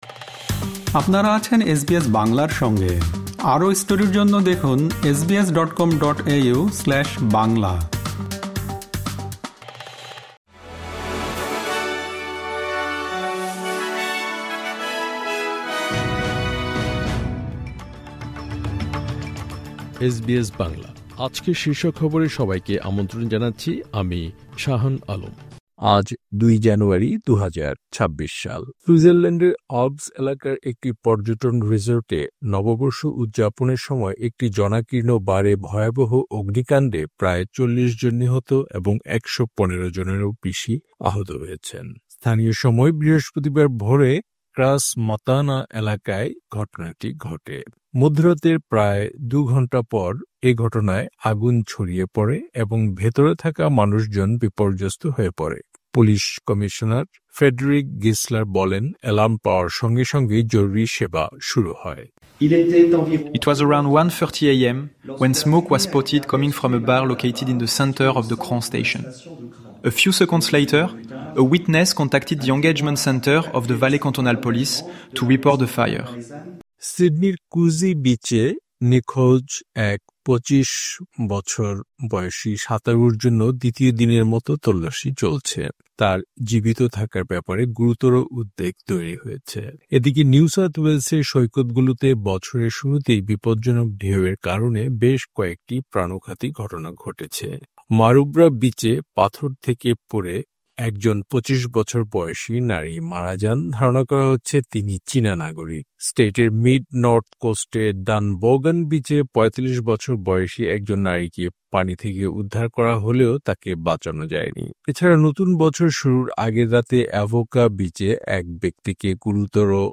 অস্ট্রেলিয়ার জাতীয় ও আন্তর্জাতিক সংবাদের জন্য আজ ২ জানুয়ারি, ২০২৬-এর এসবিএস বাংলা শীর্ষ খবর শুনতে উপরের অডিও-প্লেয়ারটিতে ক্লিক করুন।